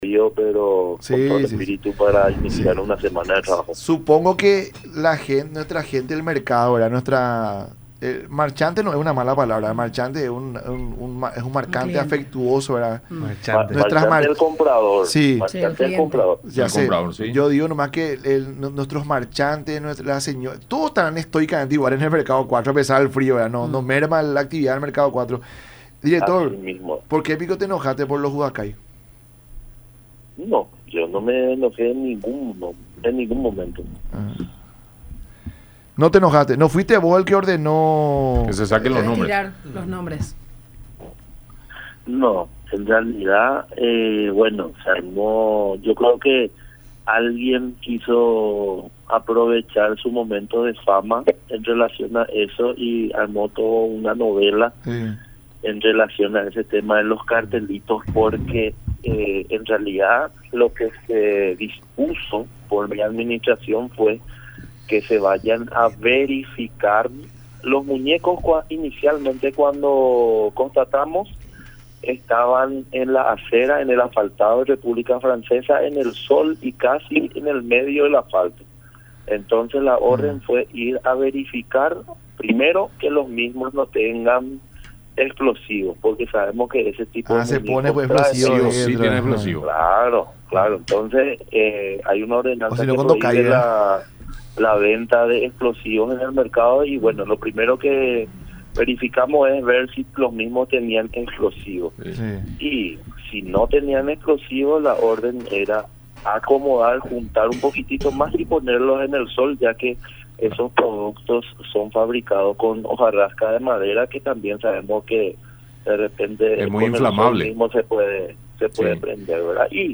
en conversación con La Mañana De Unión por Unión TV y radio La Unión